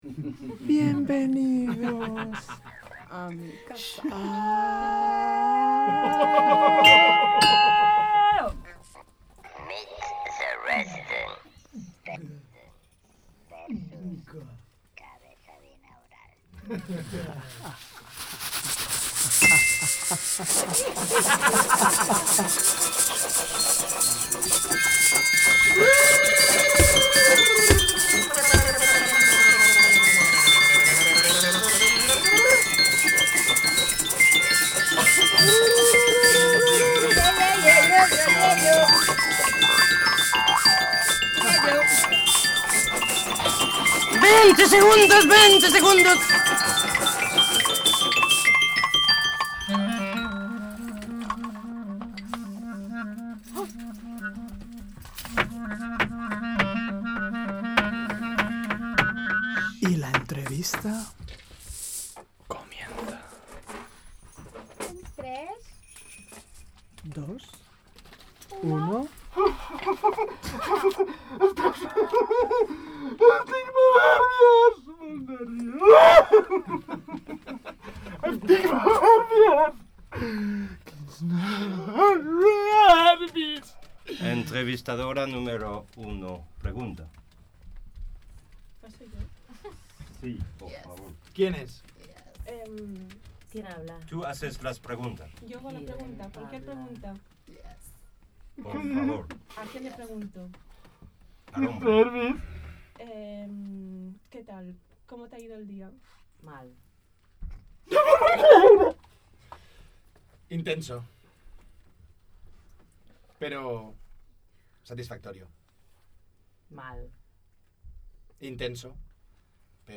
ATENCIÓ! ESCOLTAR AMB AURICULARS!